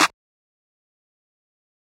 YCSizzleSlap.wav